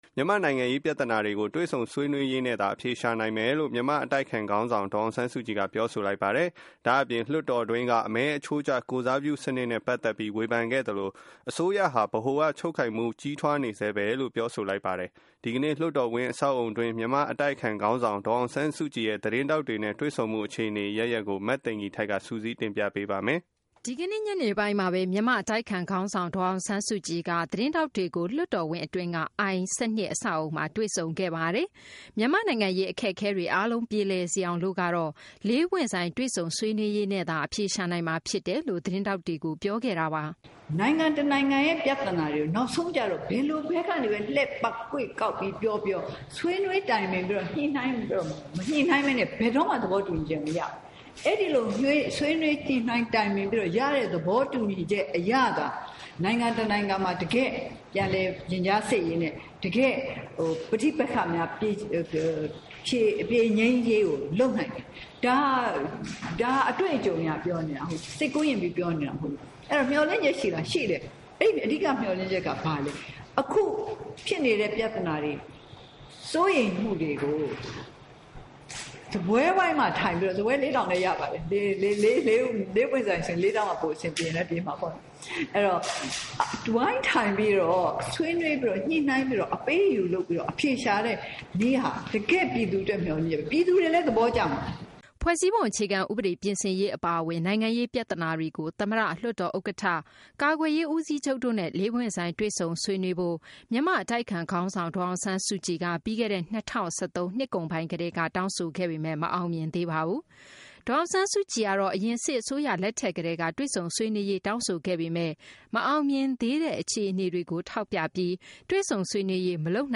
ဒေါ်အောင်ဆန်းစုကြည် သတင်းစာရှင်းလင်းပွဲ